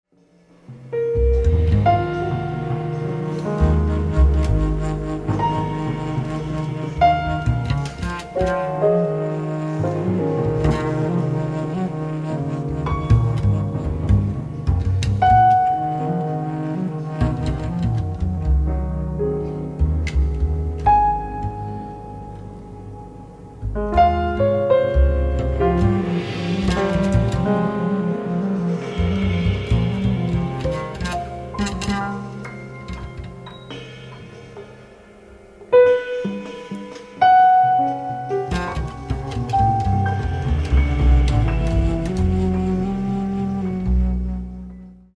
A meditative sketch type piece that